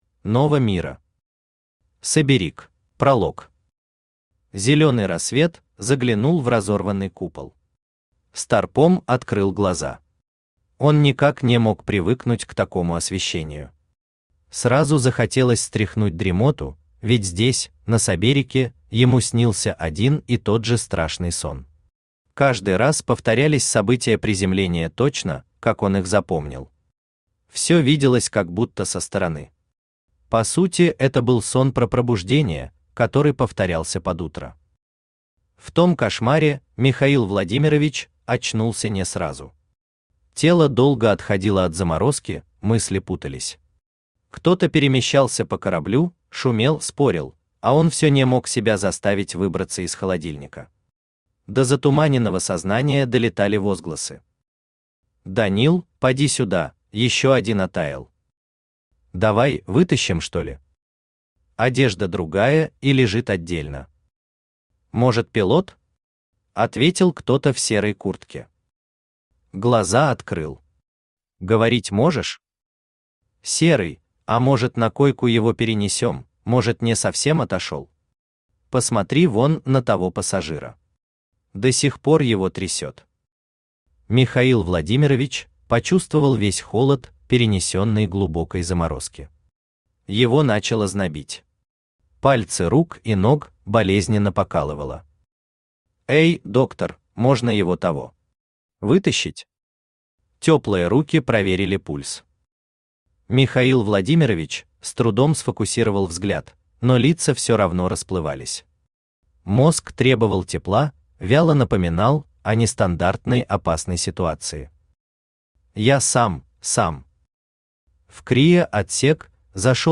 Аудиокнига Соберик | Библиотека аудиокниг
Aудиокнига Соберик Автор Нова Мира Читает аудиокнигу Авточтец ЛитРес.